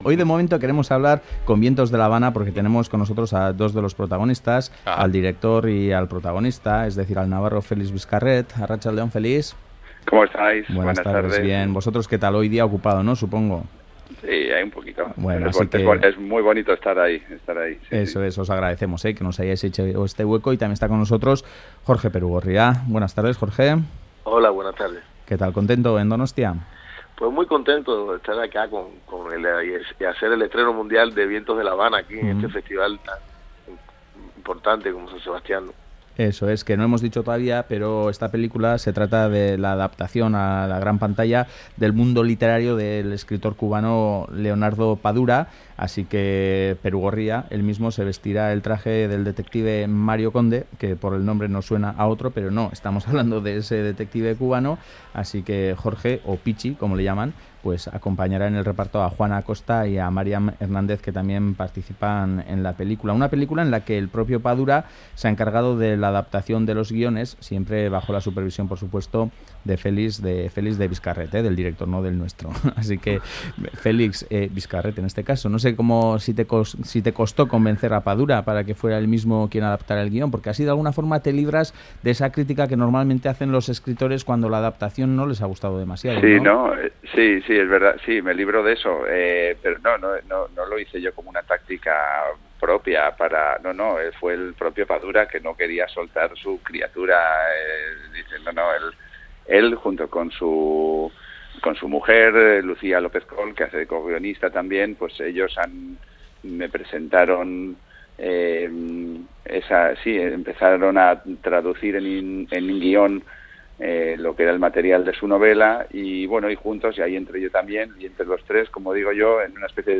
Jorge Perugorria y Félix Viscarret entrevistados en Graffiti